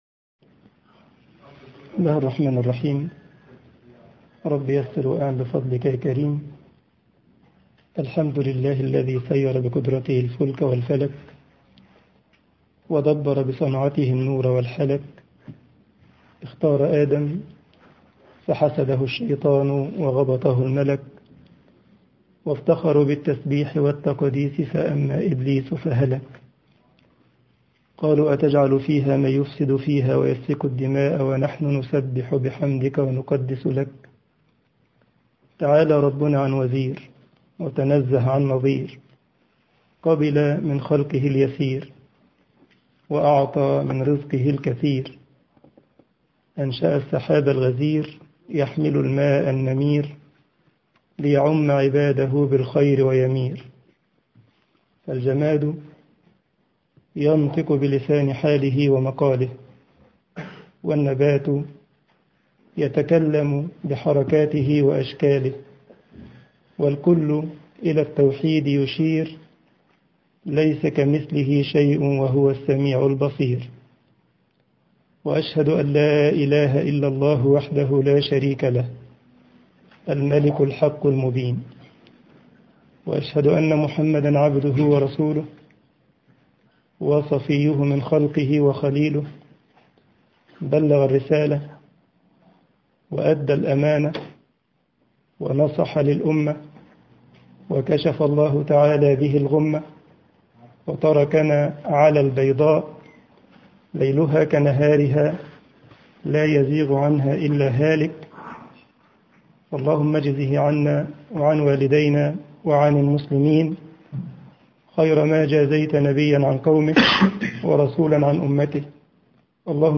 درس